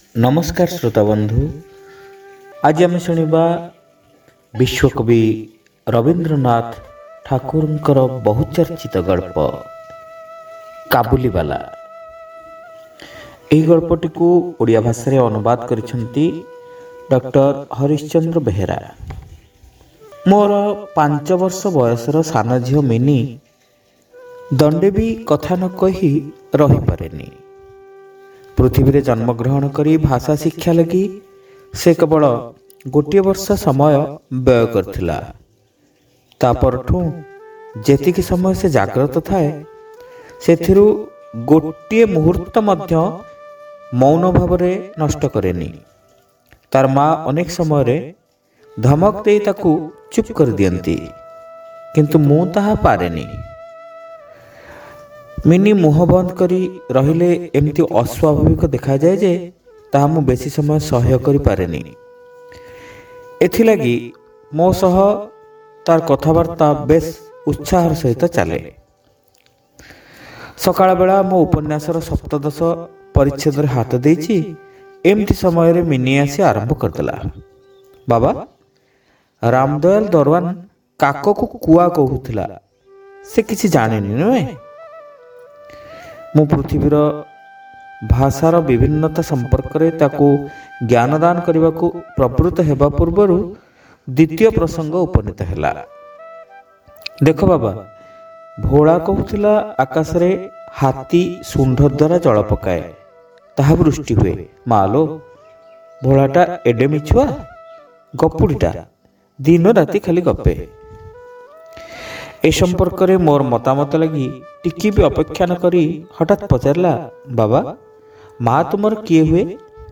ଶ୍ରାବ୍ୟ ଗଳ୍ପ : କାବୁଲିବାଲା (ପ୍ରଥମ ଭାଗ)